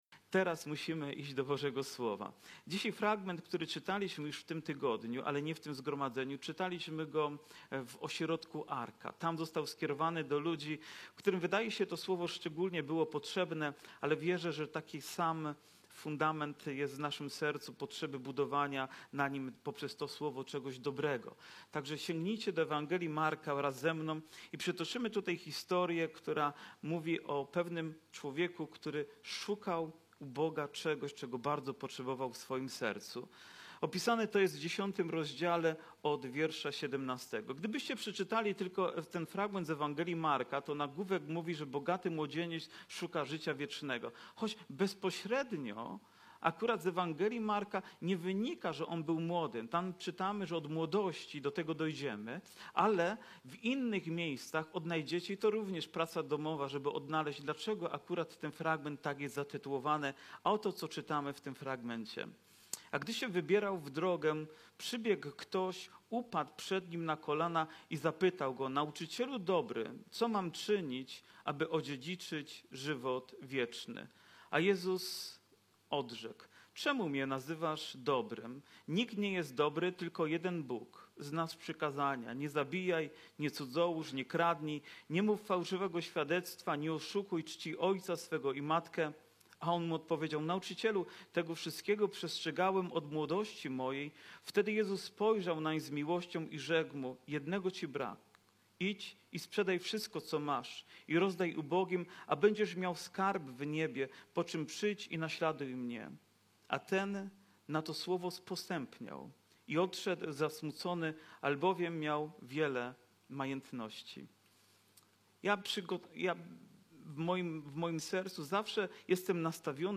Kazania , Nabożeństwo niedzielne